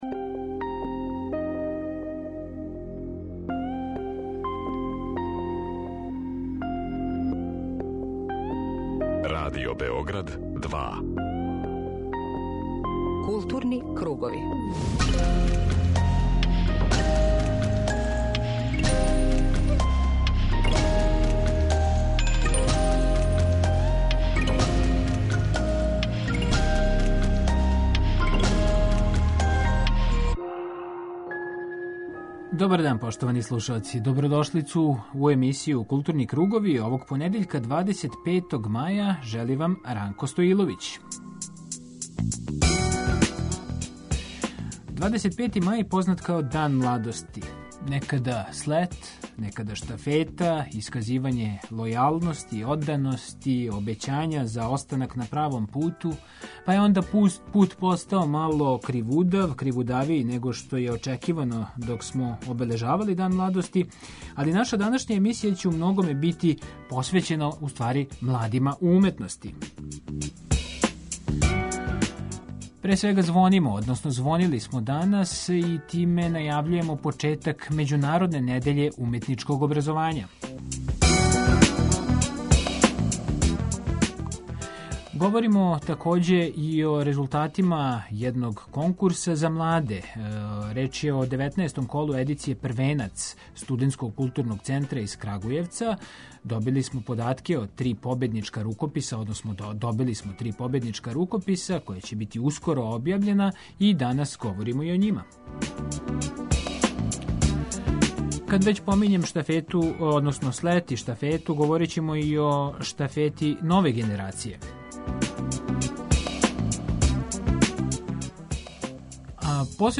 преузми : 19.71 MB Културни кругови Autor: Група аутора Централна културно-уметничка емисија Радио Београда 2.